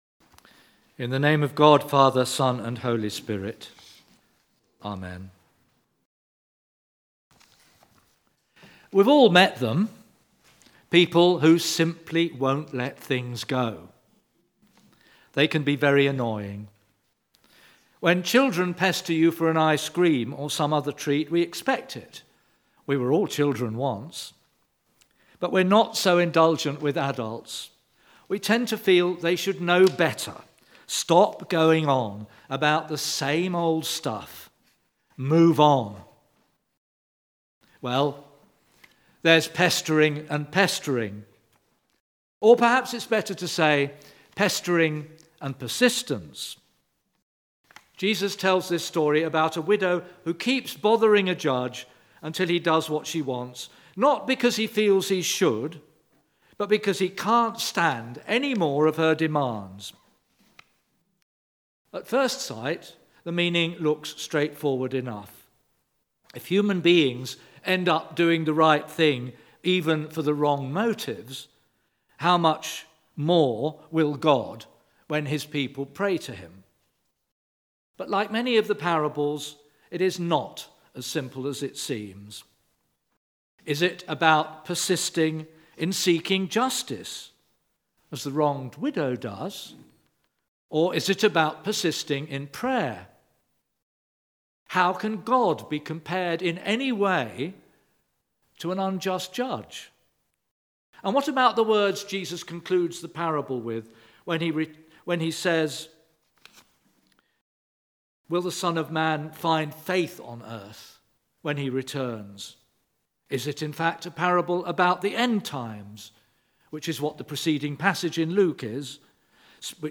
Service: Blended worship